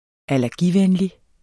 Udtale [ alæʁˈgi- ]